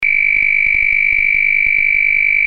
ZUMBADOR SONIDO CONTINUO Y/O INTERMITENTE
Zumbador de fijación mural
90dB